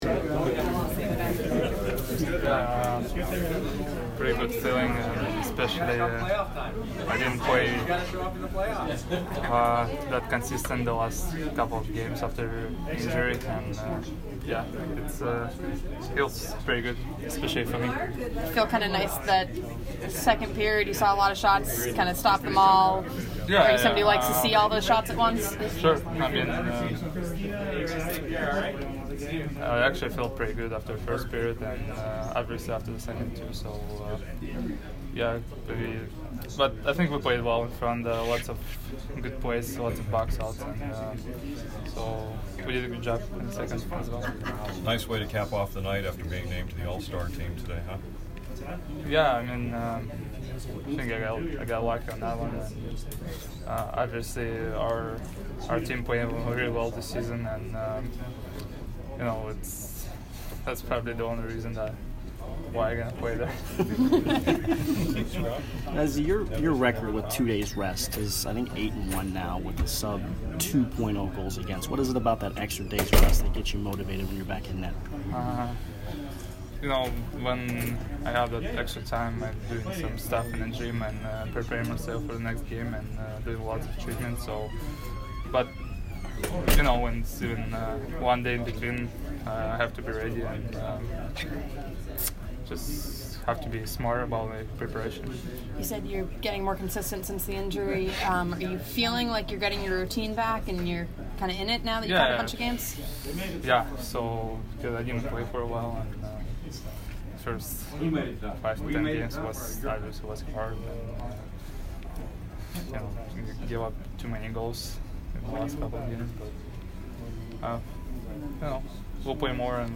Andrei Vasilevskiy post-game 1/8